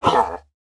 giant3.wav